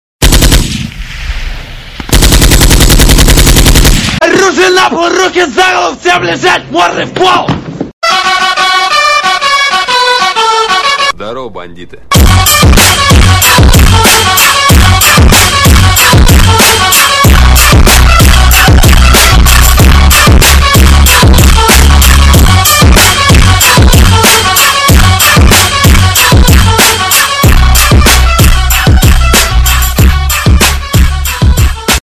На этой странице собраны реалистичные звуки стрельбы из автомата в высоком качестве.
Звук стрельбы из автомата